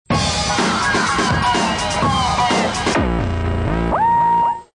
groooovy